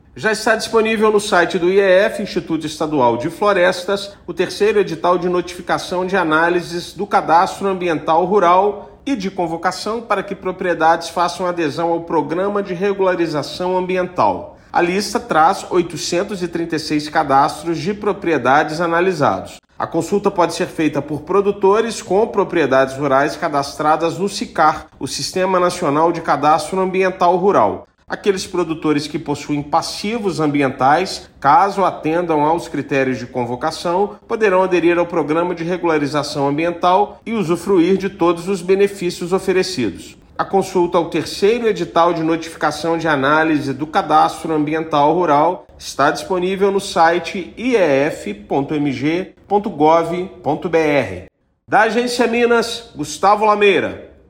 Está disponível, no site do Instituto Estadual de Florestas (IEF), o terceiro edital de notificação de análises do Cadastro Ambiental Rural (CAR) no estado e de convocação para que as propriedades façam a adesão ao Programa de Regularização Ambiental (PRA). A lista traz 836 cadastros de propriedades analisados. Ouça matéria de rádio.